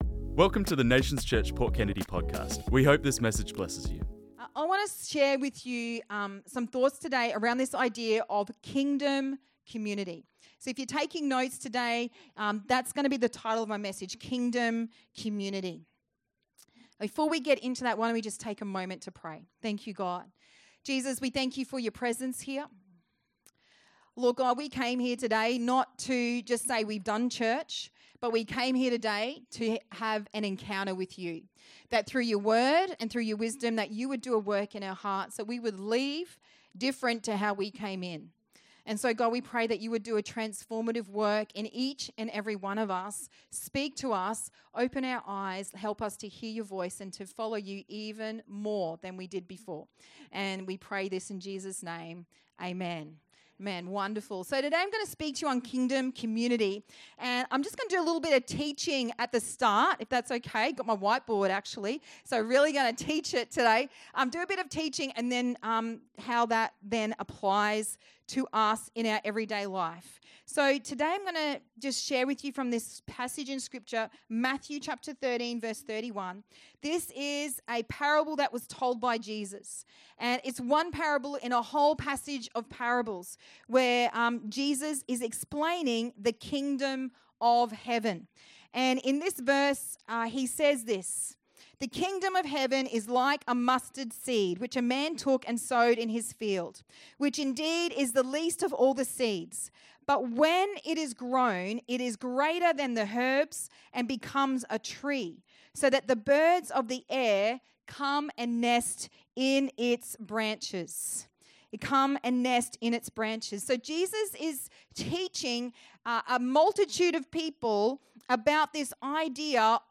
This message was preached on Sunday 9th March 2025